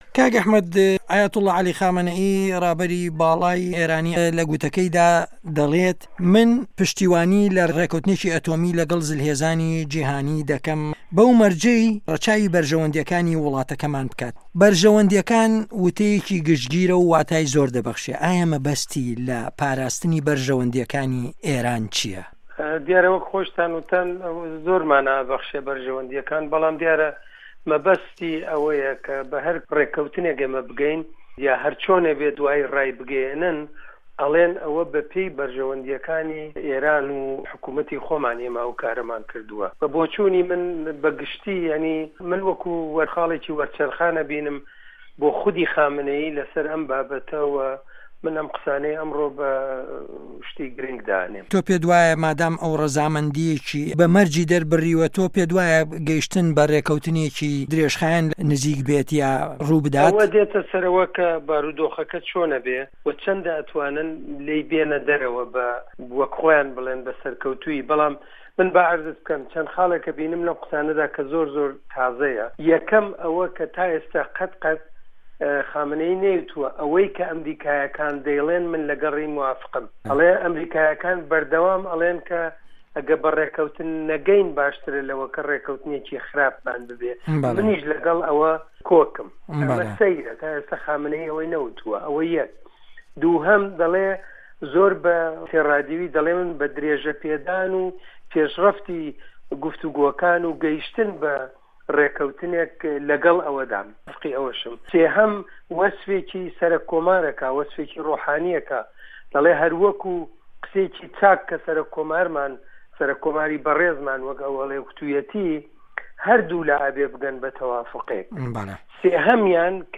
وتوێژی